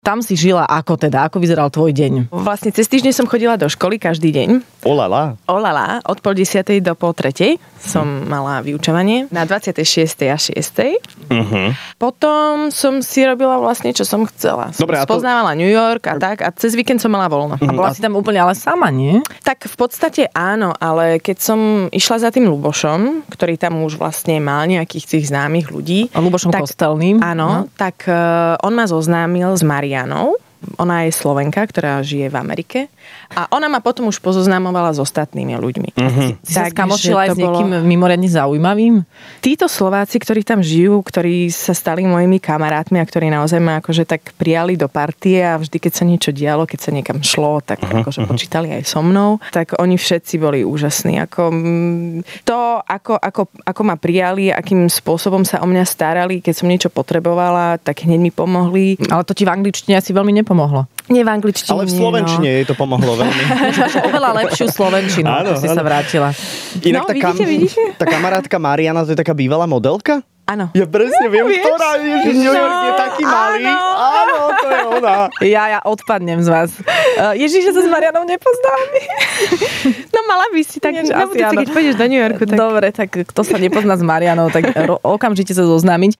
Do Rannej šou prišla populárna herečka z Ordinácie v ružovej záhrade...